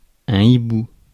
Ääntäminen
IPA : /aʊl/